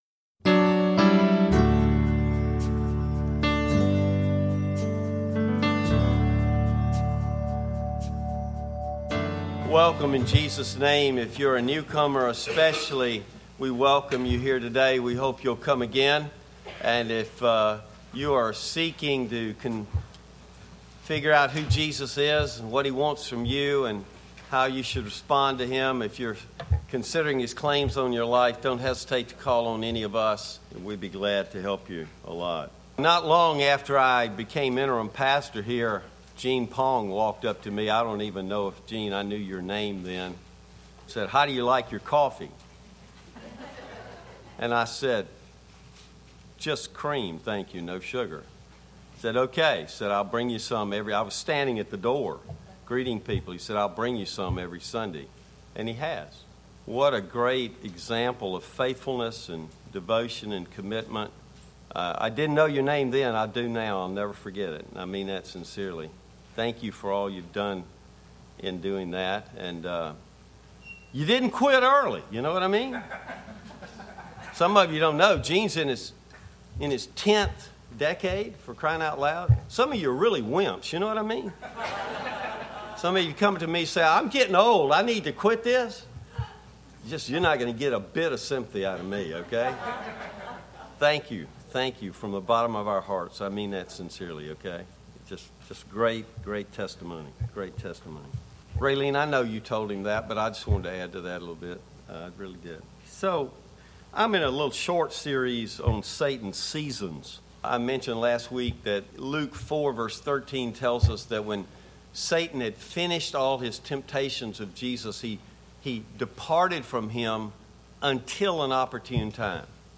Bible Text: 2 Corinthians 12:1-10 | Preacher